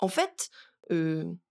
VO_ALL_Interjection_11.ogg